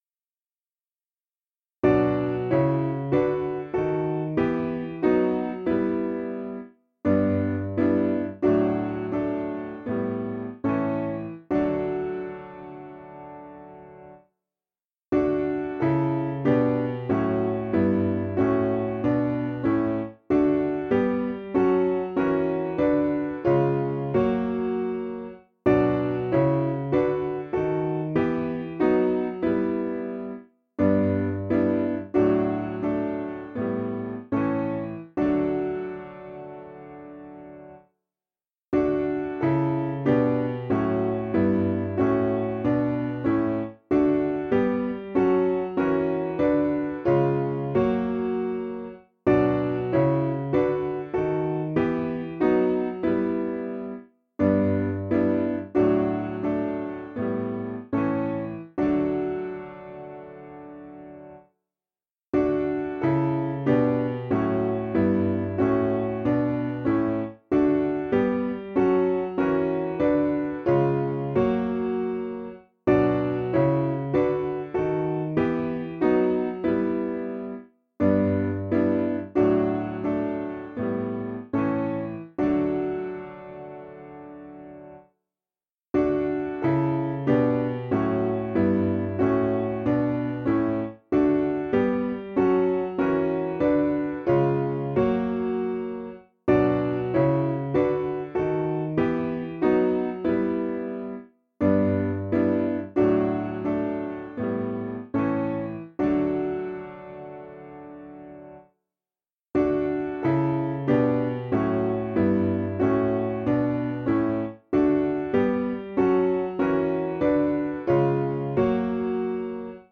Information about the hymn tune HUDDERSFIELD (Parratt).
Key: D Major